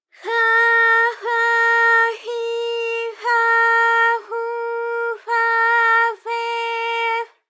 ALYS-DB-001-JPN - First Japanese UTAU vocal library of ALYS.
fa_fa_fi_fa_fu_fa_fe_f.wav